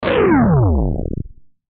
На этой странице собраны звуки резкого отключения электричества – от тихого щелчка выключателя до гула пропадающего напряжения в сети.
Звук при отключении электричества